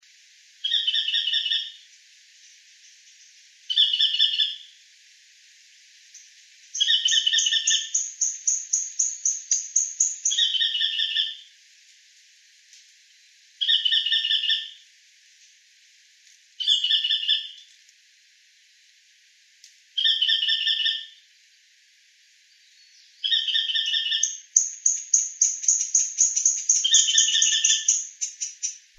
White-eyed Foliage-gleaner (Automolus leucophthalmus)
Life Stage: Adult
Location or protected area: Reserva Privada y Ecolodge Surucuá
Condition: Wild
Certainty: Recorded vocal